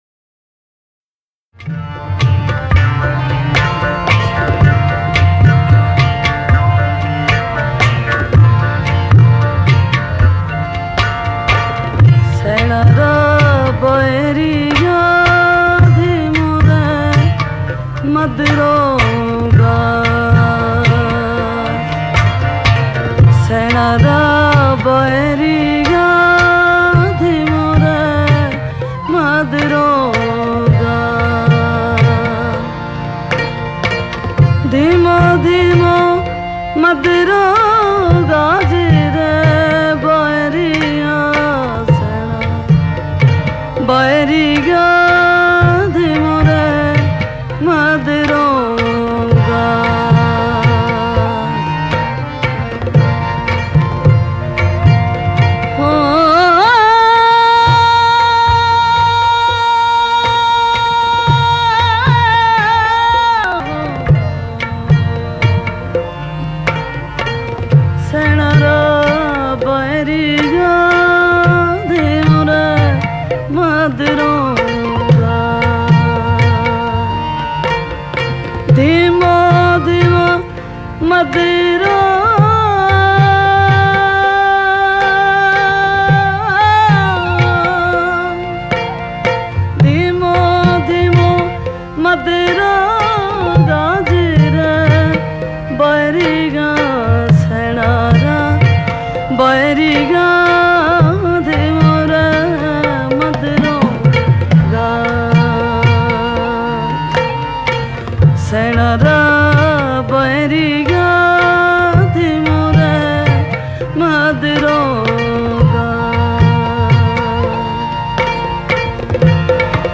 Download 8000+ Sufi MP3 Songs, Books & Art free